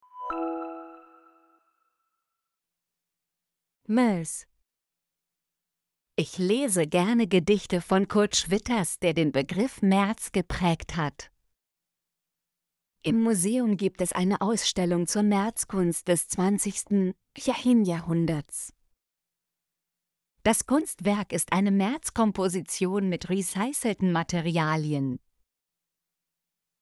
merz - Example Sentences & Pronunciation, German Frequency List